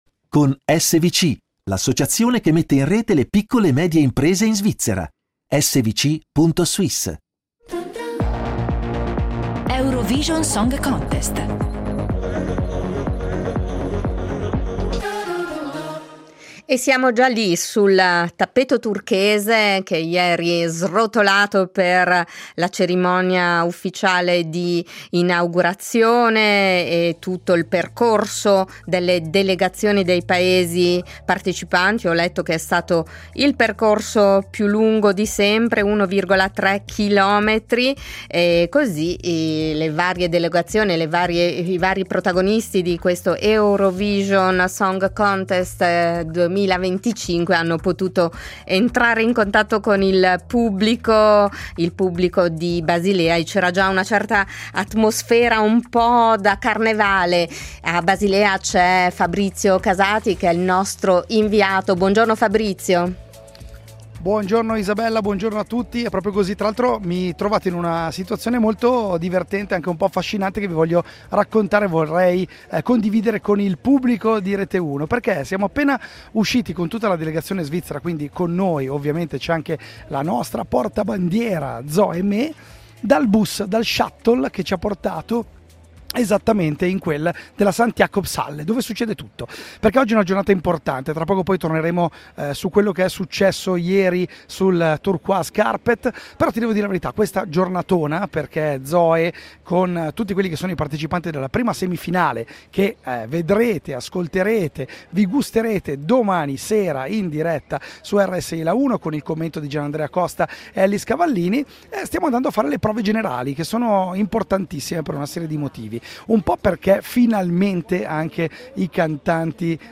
ci racconta in diretta le emozioni di questa giornata di prove generali